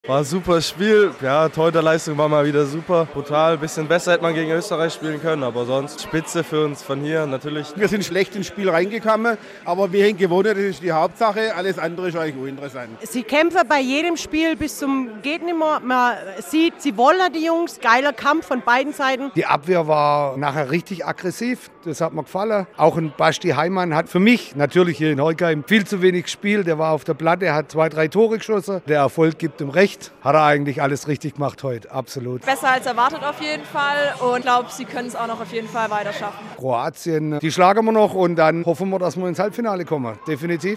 Beste Stimmung gab es am Montagabend in Heilbronn-Horkheim nach dem Sieg der deutschen Mannschaft bei der Handball-EM.
Besucher des Public Viewings in Heilbronn-Horkheim